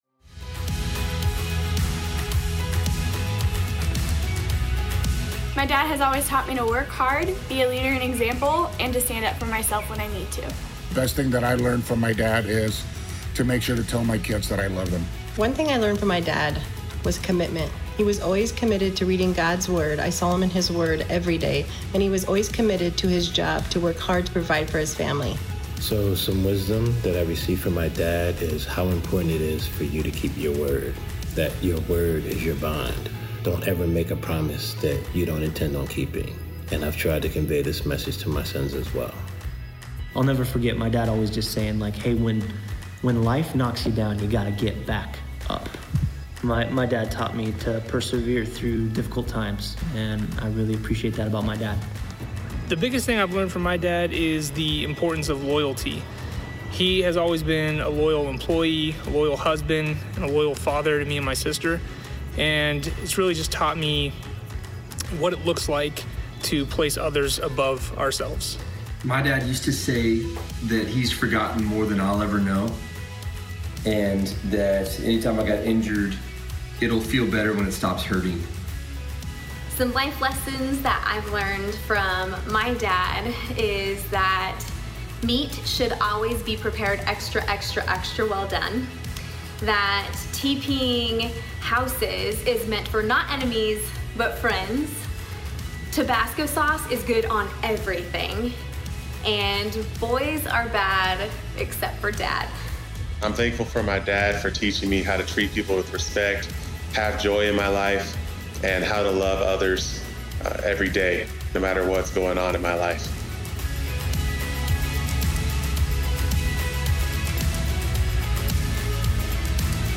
Message Only